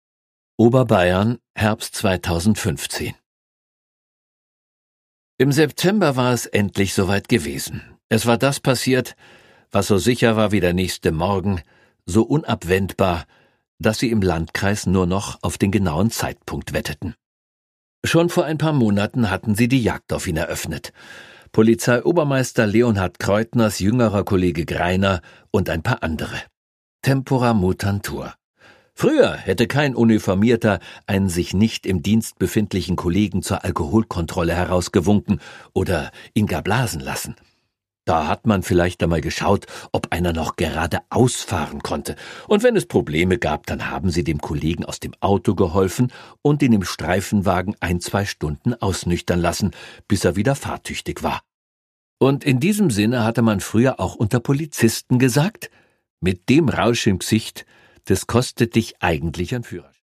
Produkttyp: Hörbuch-Download
Gelesen von: Michael Schwarzmaier